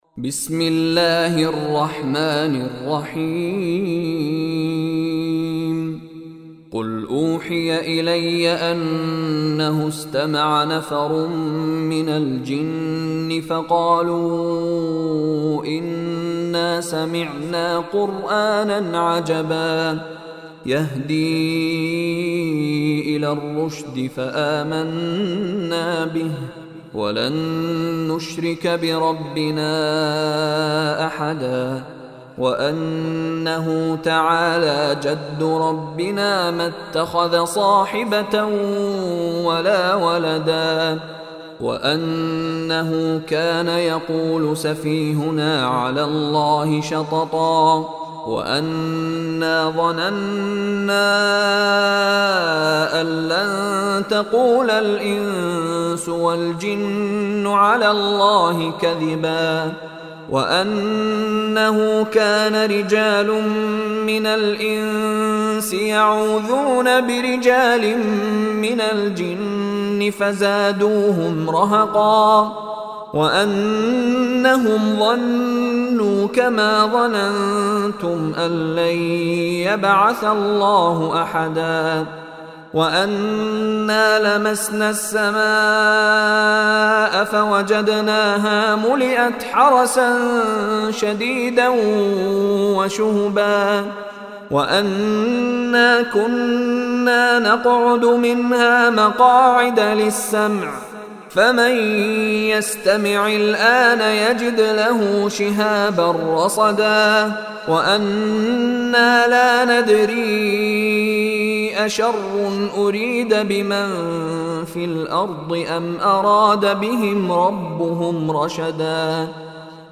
Surah Al Jinn Recitation by Mishary Rashid
Surah Al-Jinn is 72 chapter / Surah of Holy Quran. Listen online and download Quran tilawat / Recitation of Surah Al-Jinn in the beautiful voice of Sheikh Mishary Rashid Alafasy.